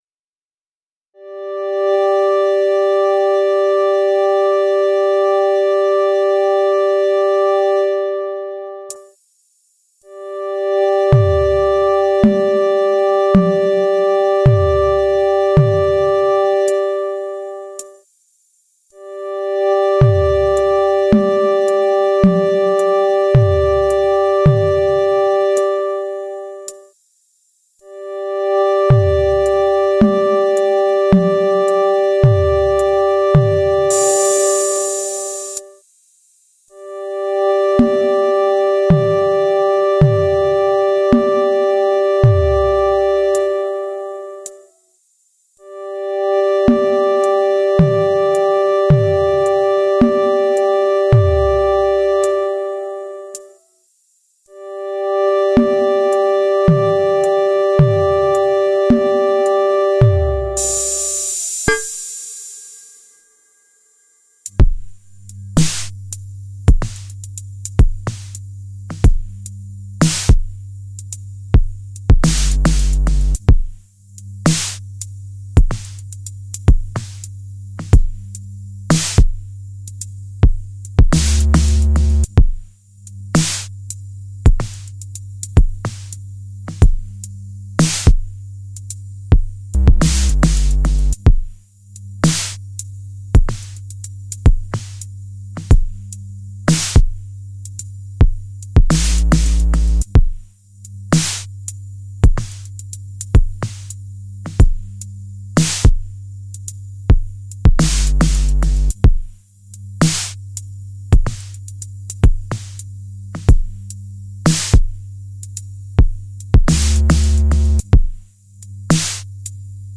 A few first bars of a song idea, targeted for a potential all-analogue-synth album. Synths are Analog Rytm, Tetra, Minibrute.